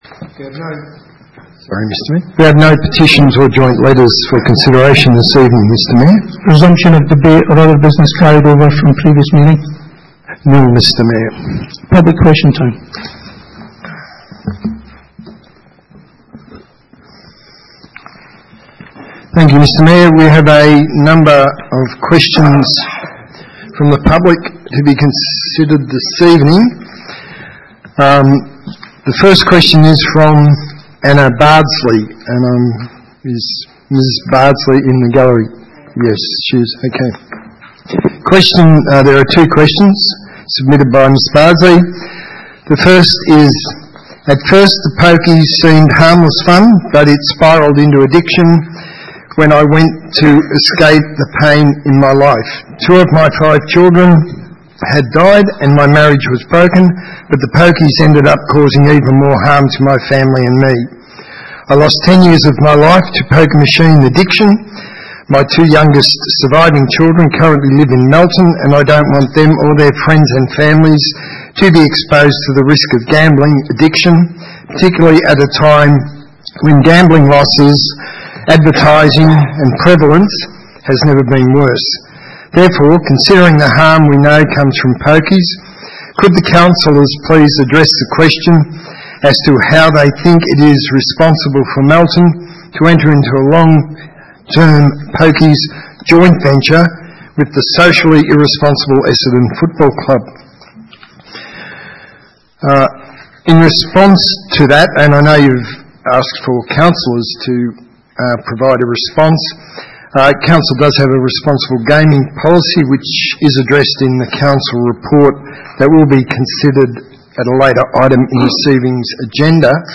Ordinary meeting 10 December 2018